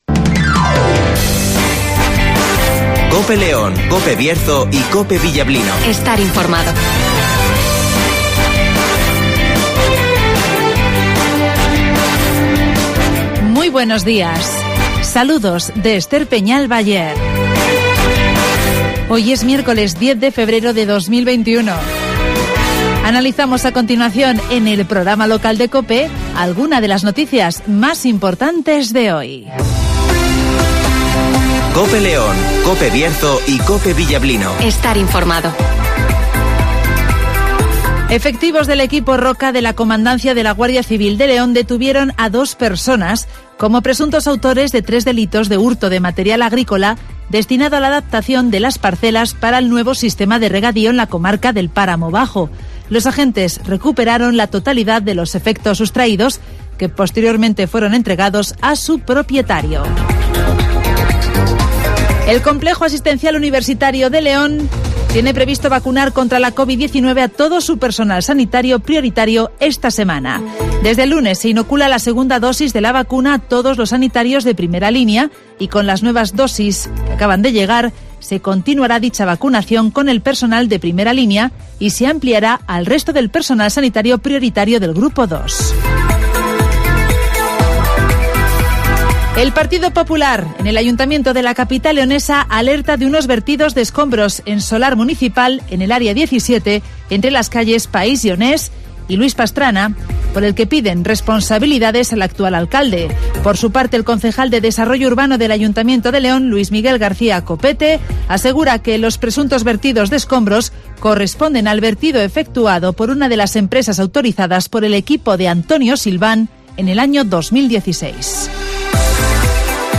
Avance informativo, El Tiempo (Neucasión) y Agenda (Carnicerías Lorpy)